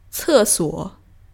ce4--suo3.mp3